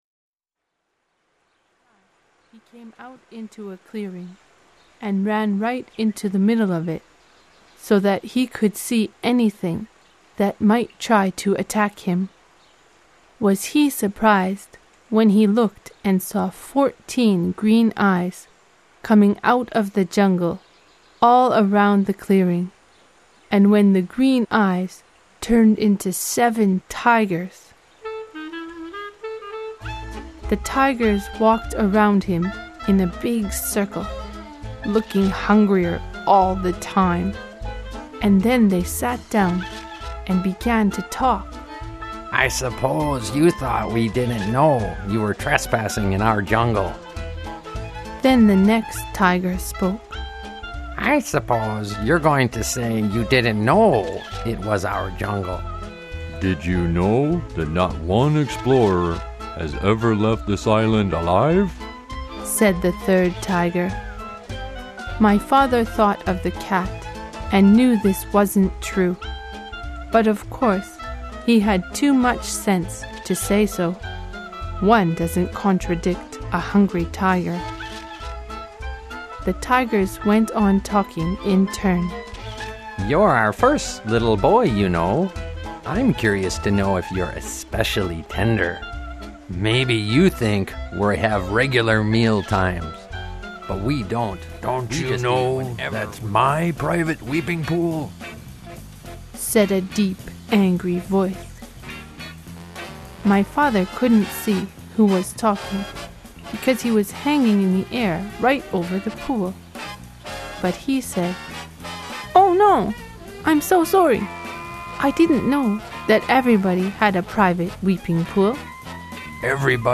外国人ナレーターの朗読による本物の英語で、英語の学習のために、
本オーディオブックは、すべて英語での朗読となっております。